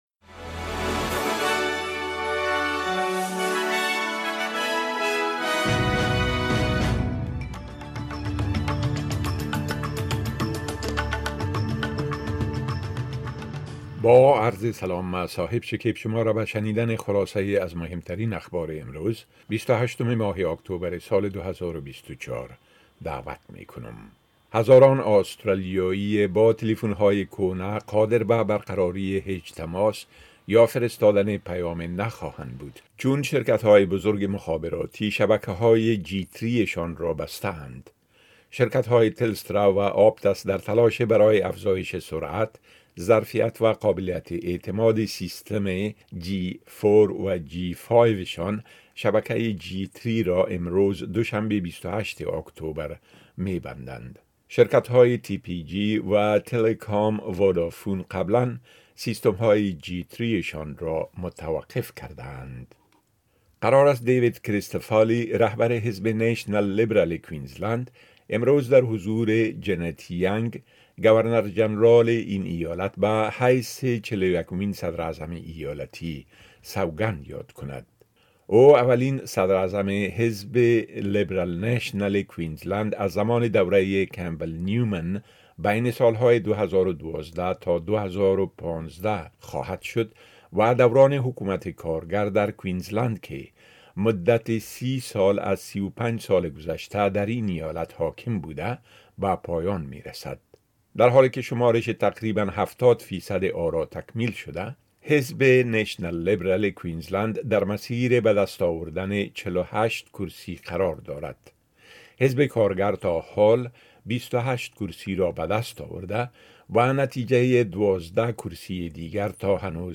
خلاصۀ مهمترين اخبار روز از بخش درى راديوى اس بى اس